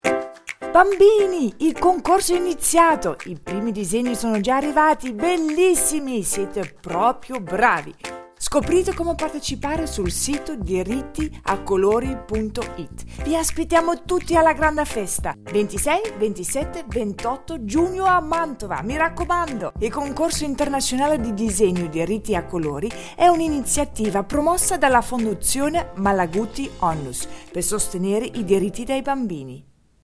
SPOT RADIO
Spot-Radio-13-Concorso-1.mp3